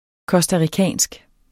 costaricansk adjektiv Bøjning -, -e Udtale [ kʌsdaʁiˈkæˀnsg ] eller [ kʌsdɑ- ] Betydninger fra Costa Rica; vedr. Costa Rica eller costaricanerne